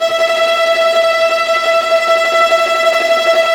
Index of /90_sSampleCDs/Roland LCDP08 Symphony Orchestra/STR_Vas Bow FX/STR_Vas Tremolo